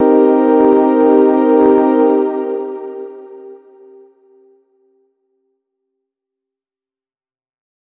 000_LOFI CHORDS MIN7_3.wav